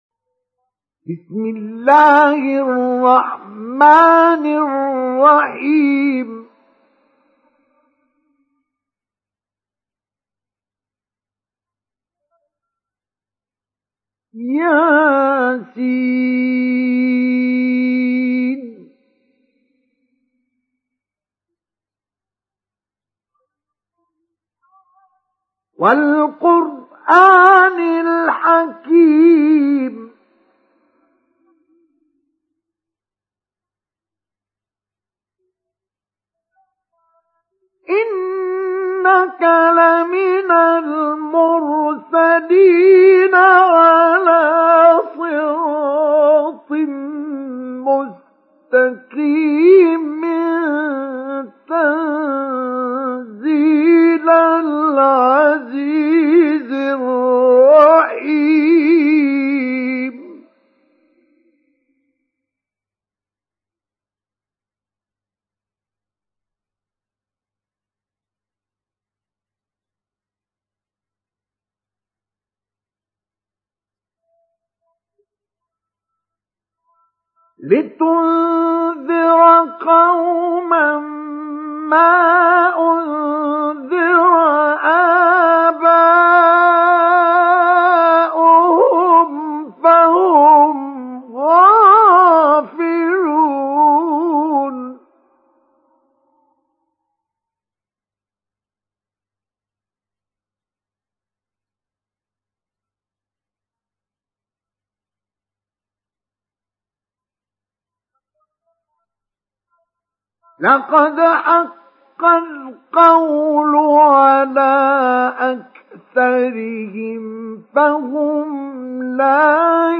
سُورَةُ يسٓ بصوت الشيخ مصطفى اسماعيل